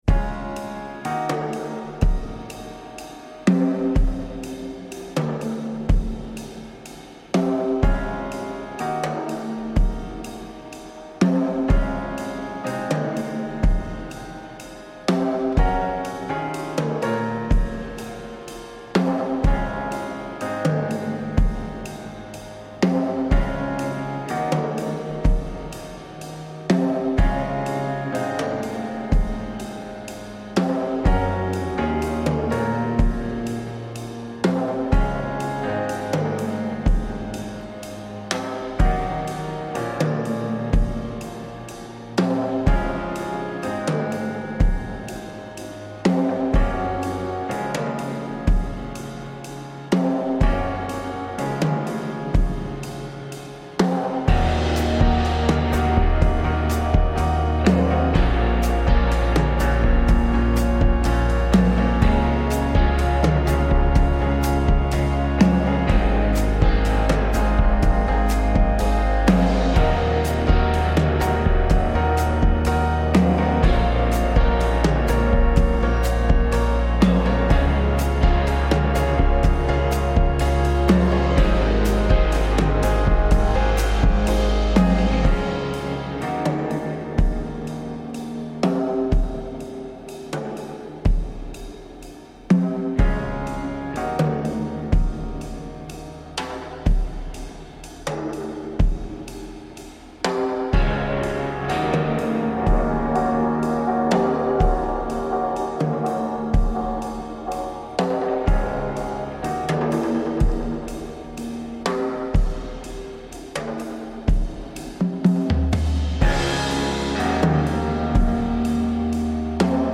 This is the music only [no lyrics] version of the song.